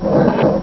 rollball.wav